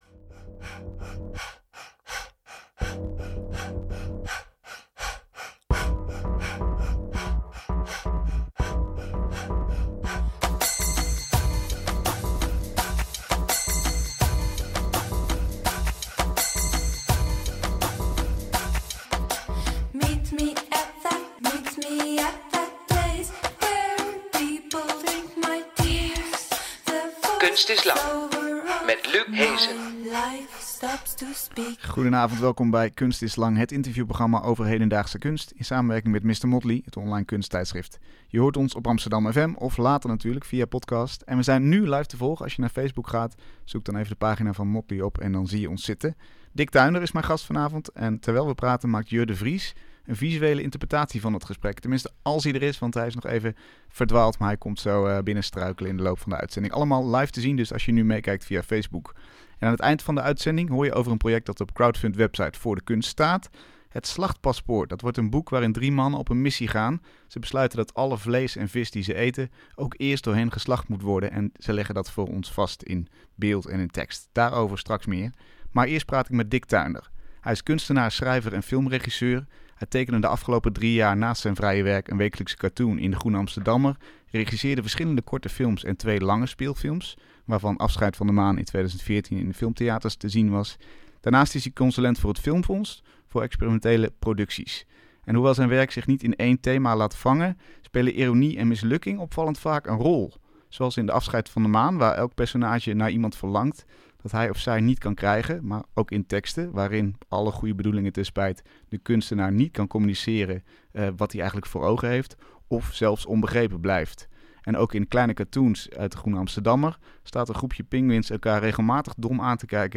Zijn personages reizen als vrienden met hem mee, en vormen een belangrijk deel van zijn sociale leven. Een gesprek over mislukking als thema, en kunst als spiegel voor het leven.